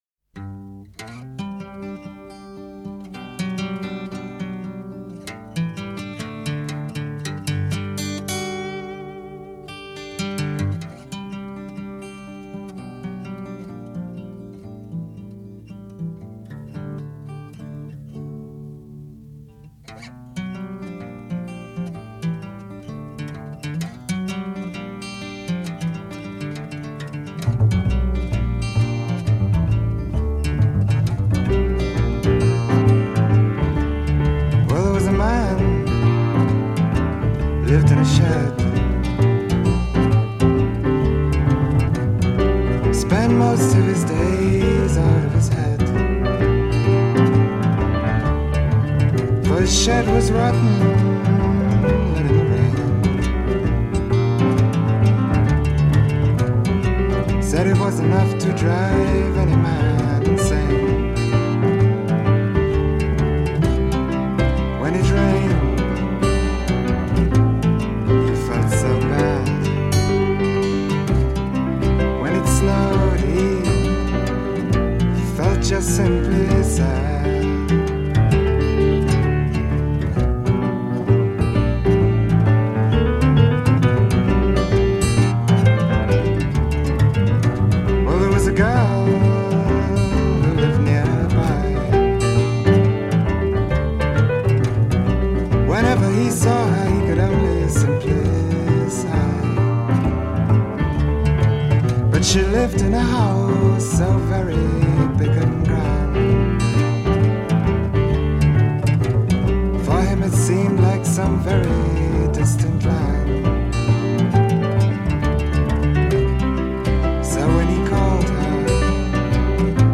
recorded in 1969 and featured on his debut album